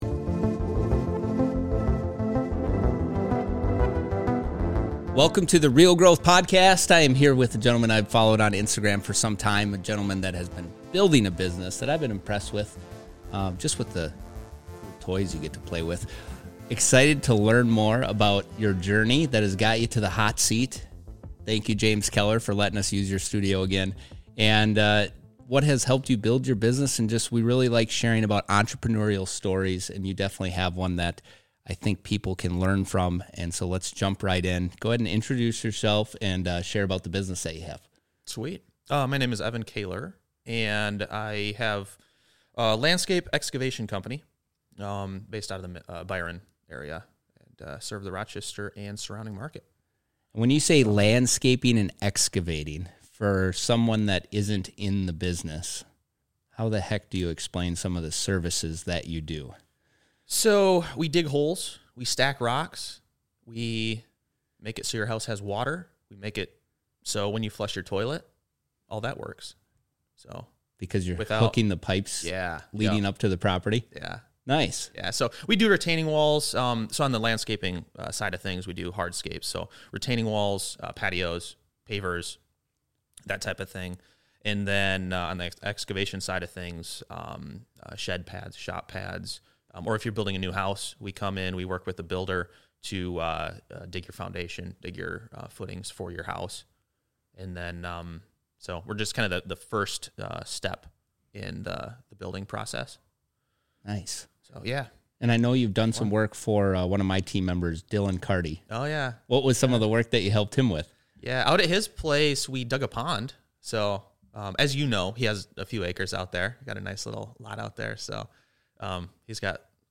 He was a delight to spend time with in the studio and hope you enjoy this chat.